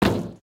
sounds / material / human / step / tin3.ogg